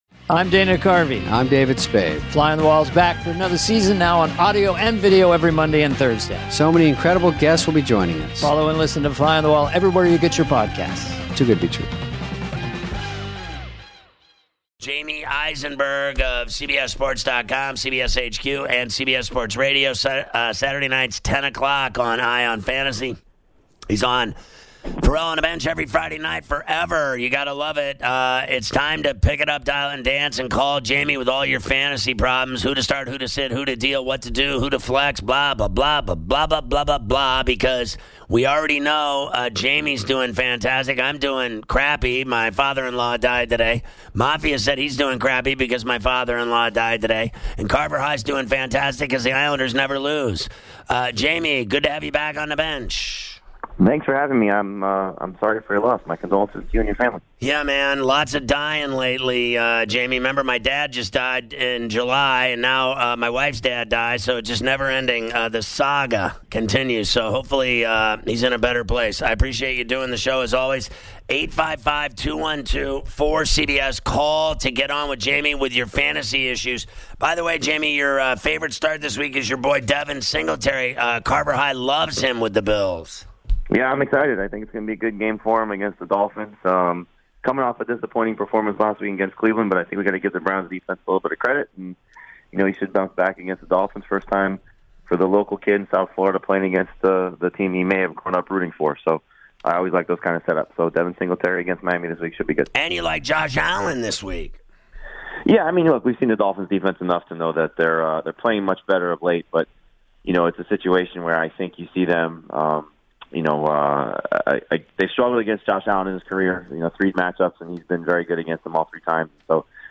take calls from the fans and help set their week 11 fantasy football lineups
Interview